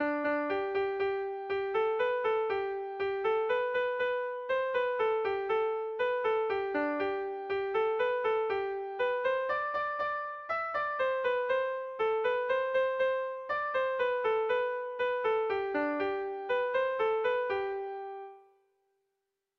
Erromantzea
Sei puntuko berdina, 10 silabaz
ABDEF